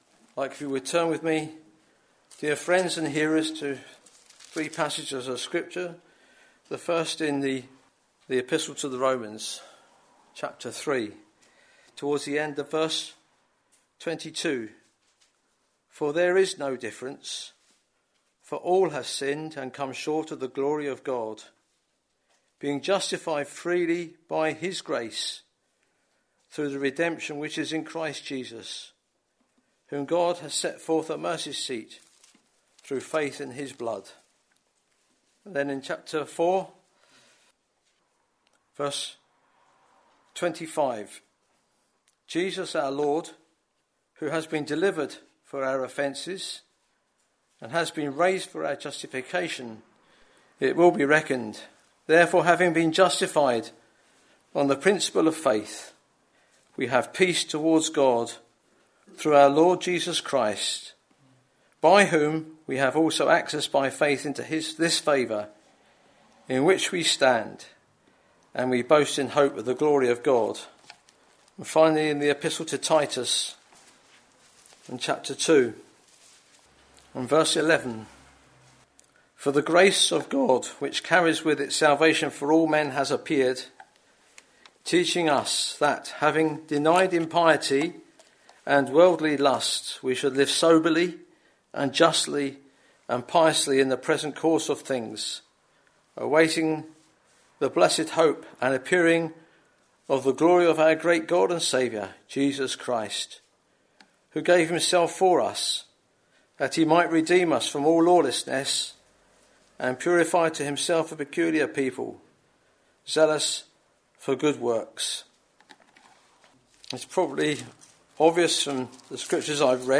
Listen to this Gospel preaching and discover how you can be saved by the work Jesus completed on Calvery's cross.